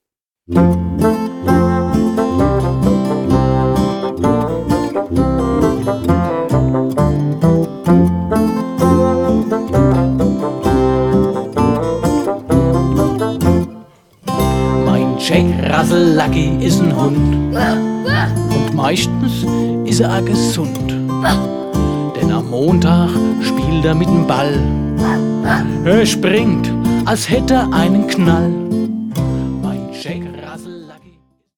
lt;p>Singen, lachen Spaß haben!